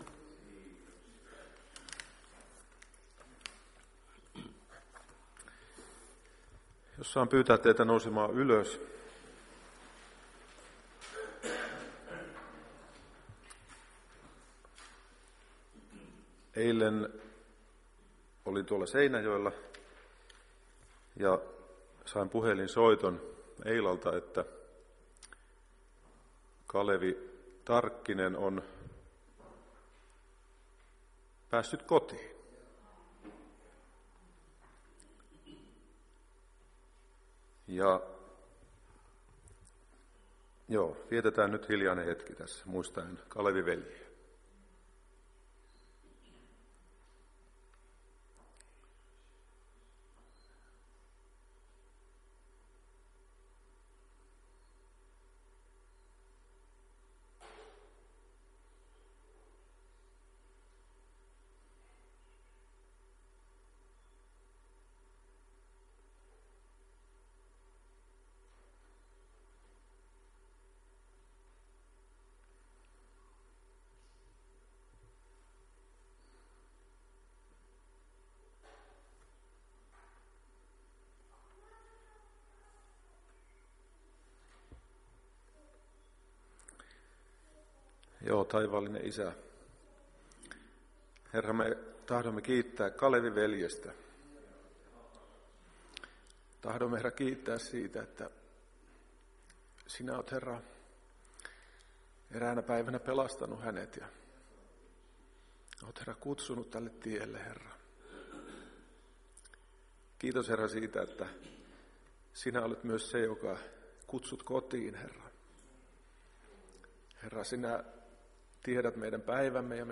Iltapäiväkokous 15.8.2021
Sunnuntain 15.8.2021 iltapäivätilaisuus